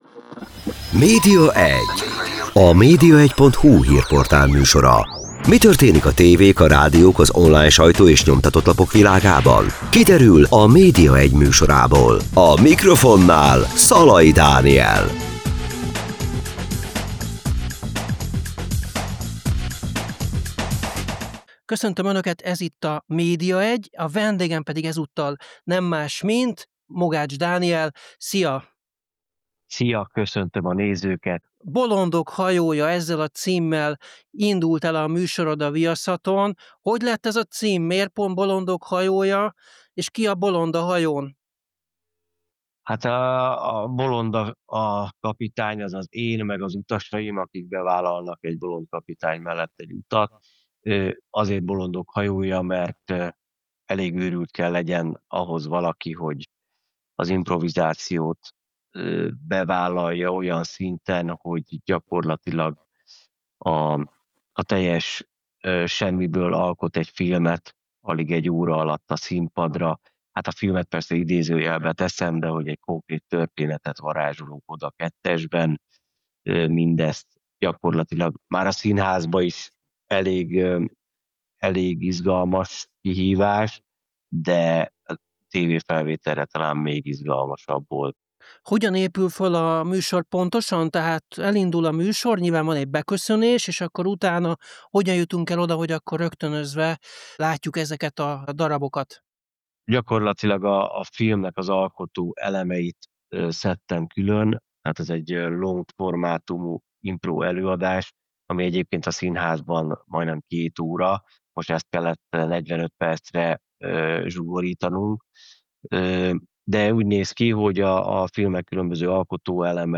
A Media1 Podcast vendége volt Mogács Dániel humorista. Elsősorban új televíziós műsoráról, a Bolondok hajója című improvizációs show-ról beszélgettünk, de több más érdekesség is szóba került. Hogyan lehet a semmiből egyetlen óra alatt történetet teremteni?